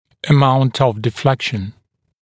[ə’maunt əv dɪ’flekʃn][э’маунт ов ди’флэкшн]степень отклонения (дуги)